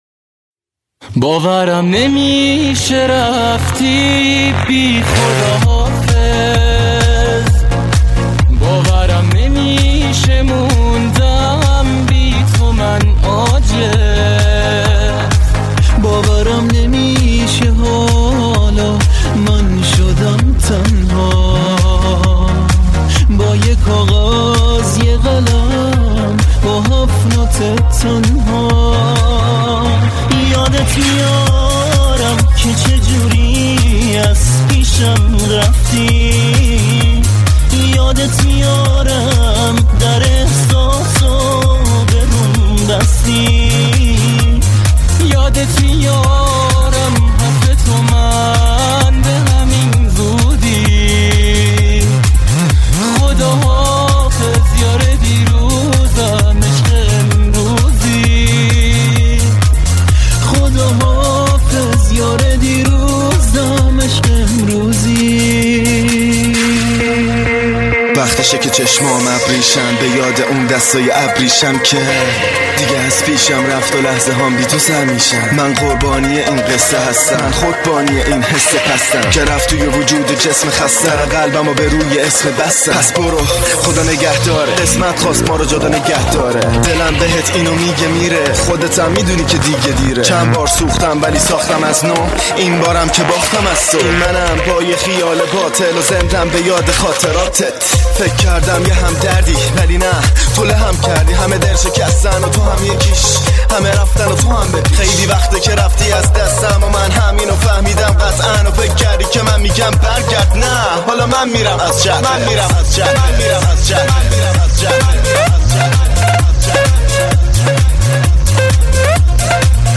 پارت رپ